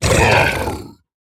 Minecraft Version Minecraft Version latest Latest Release | Latest Snapshot latest / assets / minecraft / sounds / mob / piglin_brute / death3.ogg Compare With Compare With Latest Release | Latest Snapshot
death3.ogg